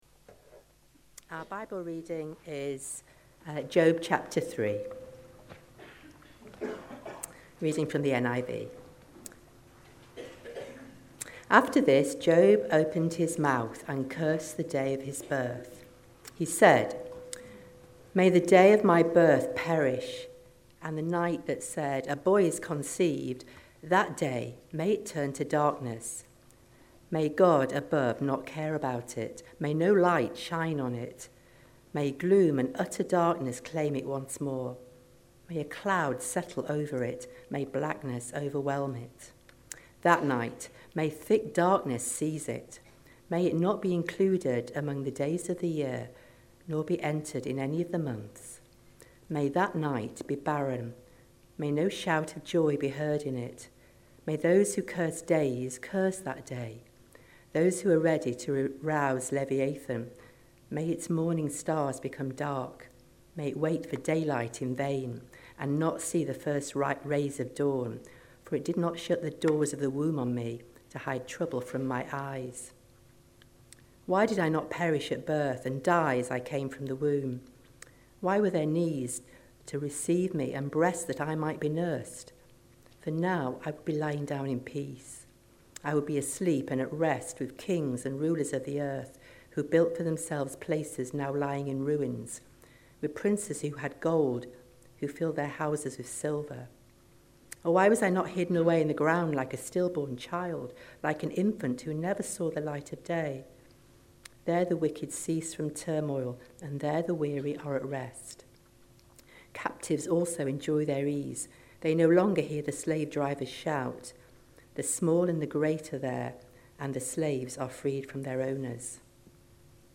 Session 2 from the 2025 annual conference - Trauma: Christ's comfort in deep suffering